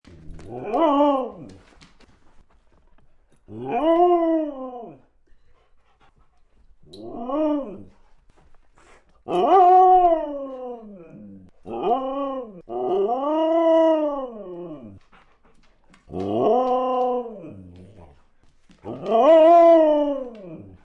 I suppose another reason Gary asked me is that since I’m a Husky, I talk more than most dogs.
freesound_community-dog-howling-78278.mp3